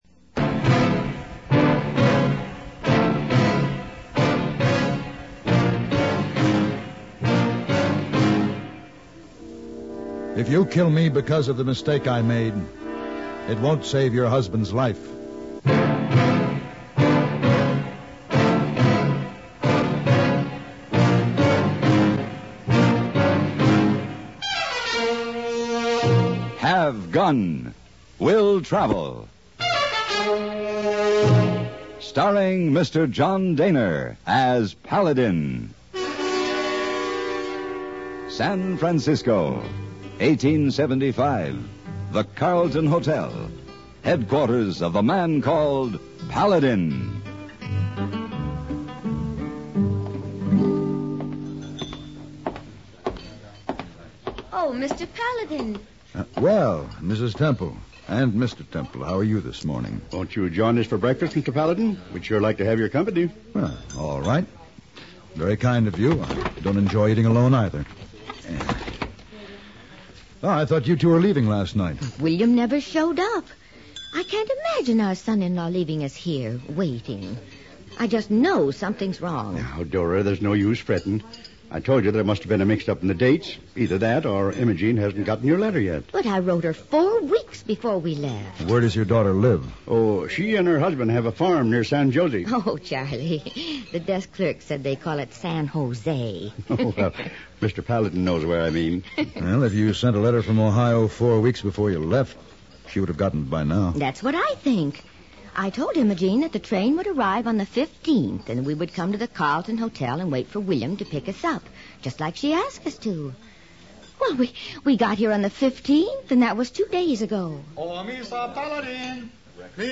Will Travel Radio Program
Starring John Dehner